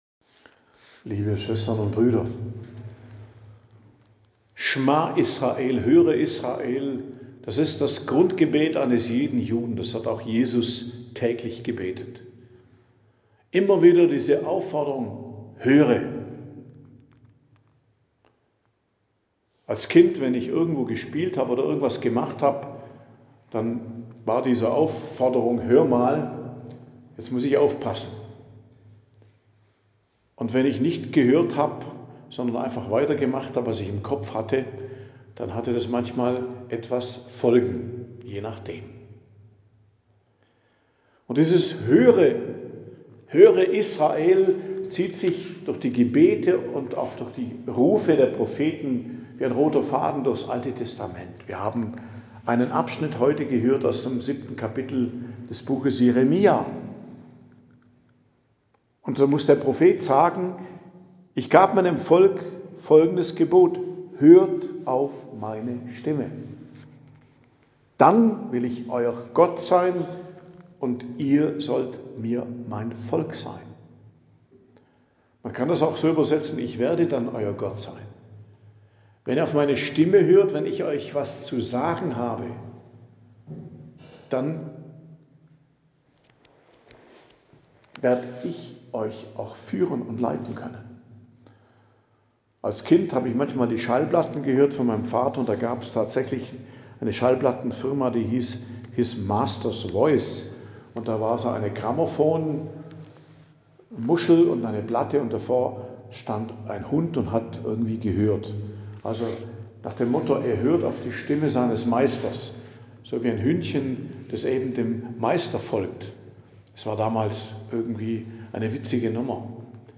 Predigt am Donnerstag der 3. Woche der Fastenzeit, 12.03.2026 ~ Geistliches Zentrum Kloster Heiligkreuztal Podcast